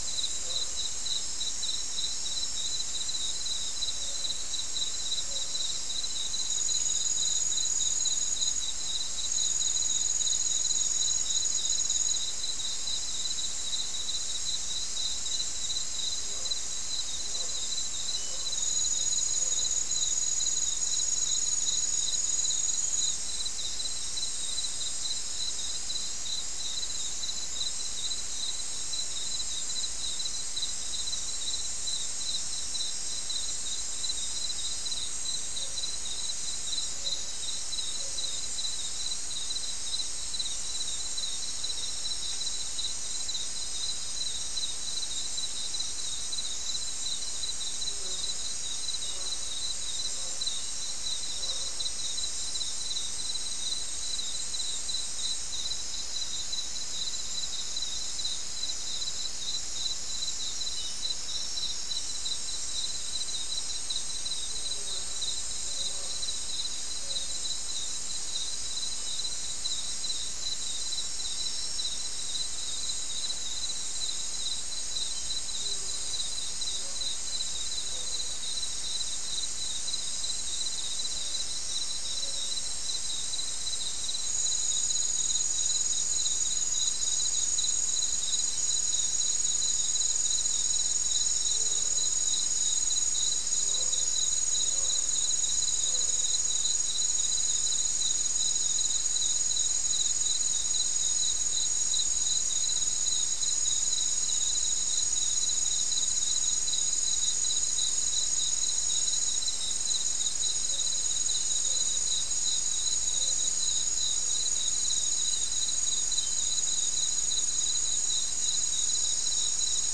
Soundscape Recording
South America: Guyana: Mill Site: 3
Recorder: SM3